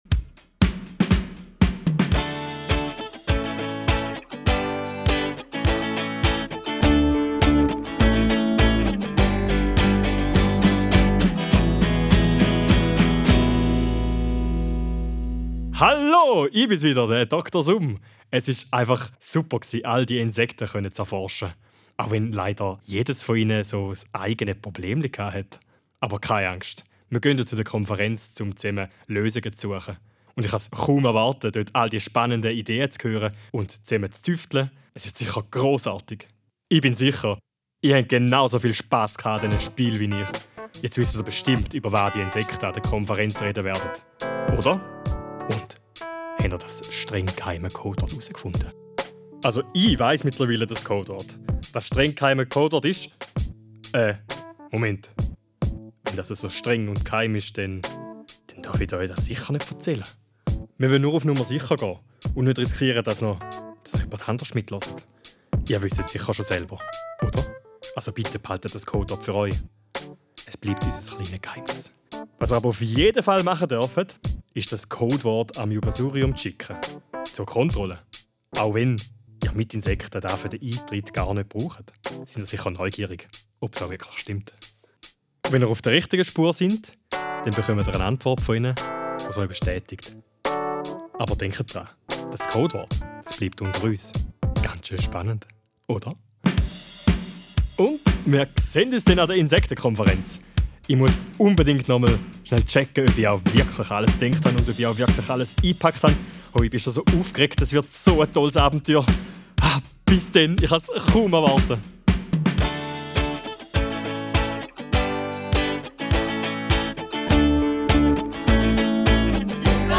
Startet mit dem letzten Hörspiel: Spielt das letzte Hörspiel durch eure Musikbox ab.